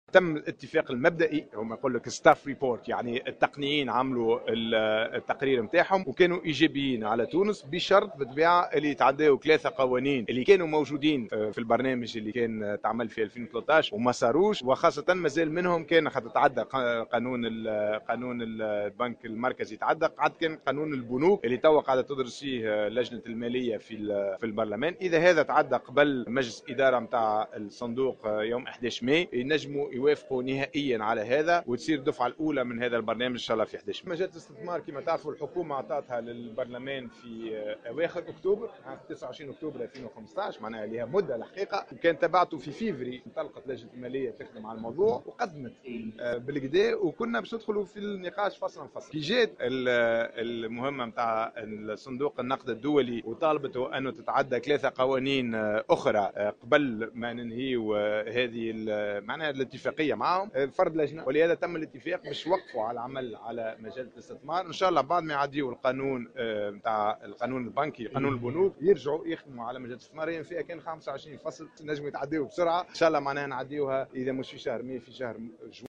وأكد وزير التنمية والاستثمار والتعاون الدولي ياسين إبراهيم، في تصريح لمراسلة الجوهرة أف أم على هامش هذه الندوة ان الحكومة، توفقت في تمرير قانون الشراكة بين القطاعين العام والخاص وقانون البنك المركزي ويدرس مجلس نواب الشعب حاليا قانون البنوك وكذلك مجلة الاستثمار، مبينا ان عدة بلدان في العالم لا تمرر قوانينا بالوتيرة التي تقوم بها تونس حاليا، وفق تعبيره.